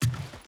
Wood Jump.ogg